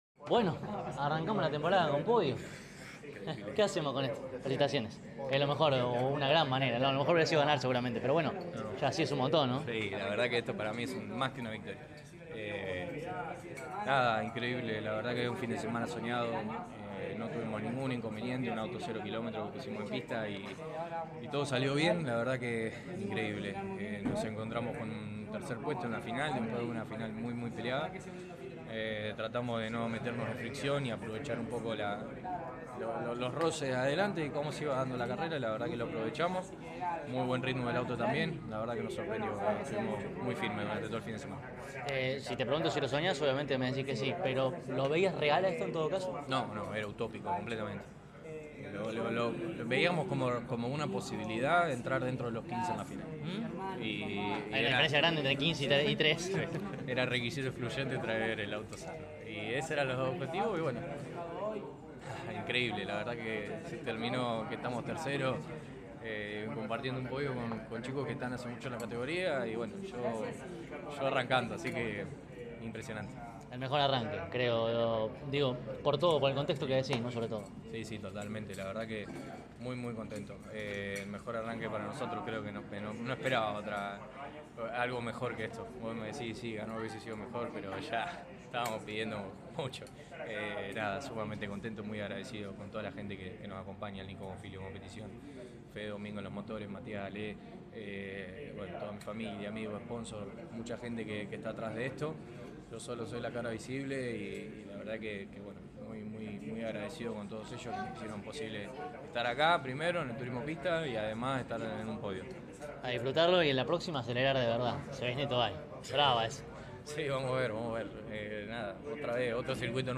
CÓRDOBA COMPETICIÓN estuvo allí presente y dialogó con los protagonistas más importantes al cabo de cada una de las finales.